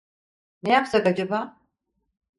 Pronounced as (IPA) /ˈɑ.d͡ʒɑ.bɑː/